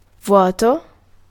Synonyymit unoccupied clear void clean leer devoid toom bare vacant lonely pour out empty out Ääntäminen : IPA : /ˈɛmp.ti/ US : IPA : [ˈɛmp.ti] UK : IPA : [ˈɛmp.ti] Tuntematon aksentti: IPA : /ˈemptiː/